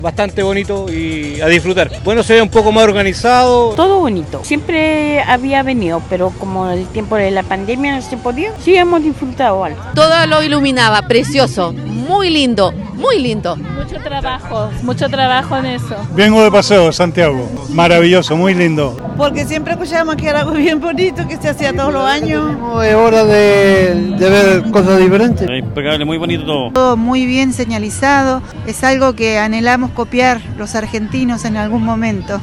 Radio Bío Bío conversó con asistentes, quienes quedaron maravillados con el evento en general.